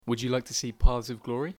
Examples of RP
//wʊd ju laɪk tə siː pɑːðz əv ˈglɔːri//
Notice that the plural of paths ends with a voiced alveolar fricative even though the singular ends with a voiceless dental fricative as you will hear in the next example.
05_RP_(1).mp3